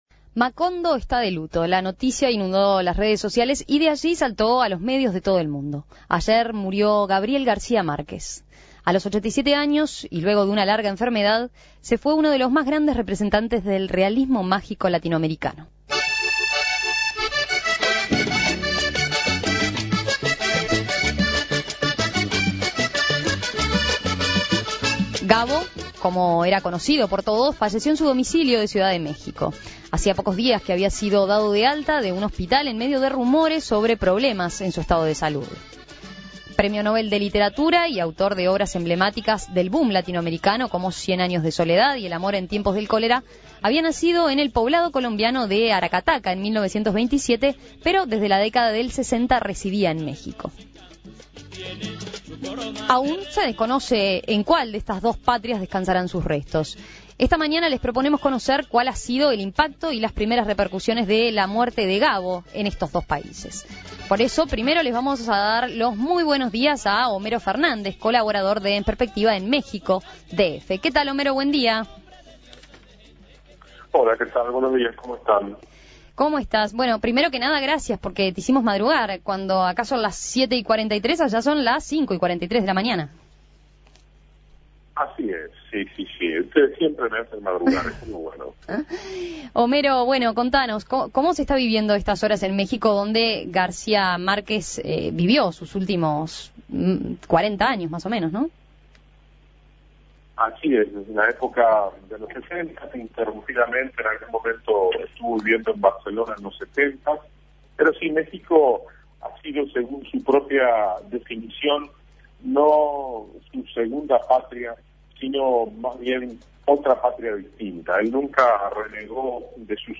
(Voz de hombre.)
(Voz de mujer.)
(Voz de Gabriel García Márquez leyendo ‘Cien años de soledad’.)